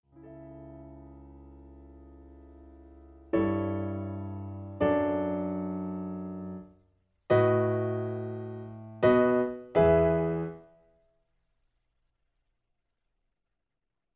Original Key (A). Piano Accompaniment